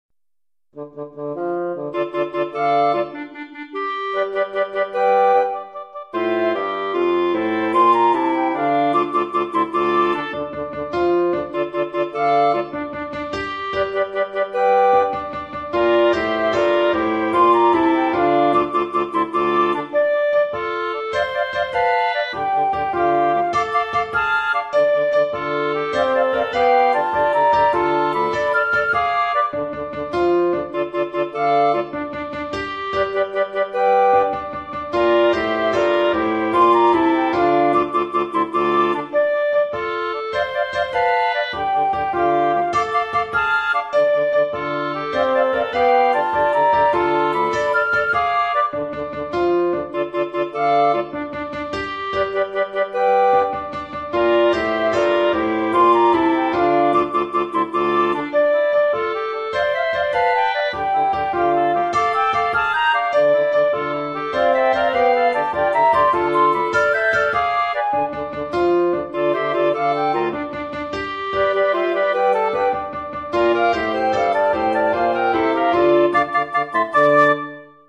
4.-Noticies-destar-per-casa-Instrumental.mp3